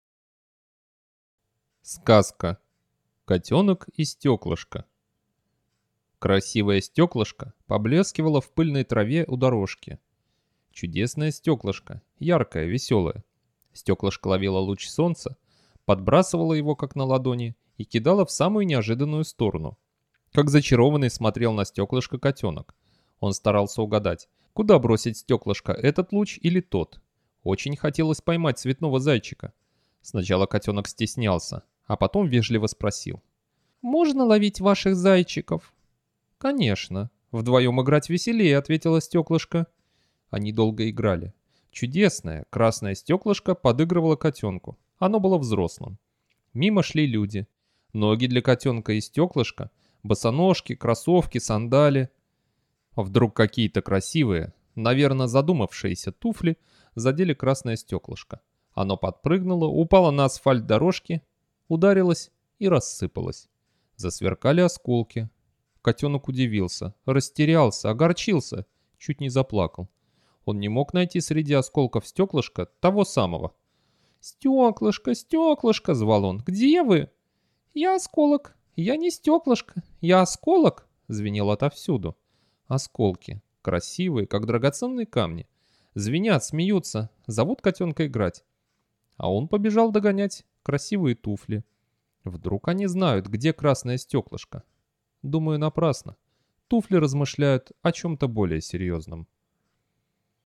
Аудиосказка «Котенок и стеклышко»